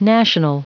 Prononciation du mot national en anglais (fichier audio)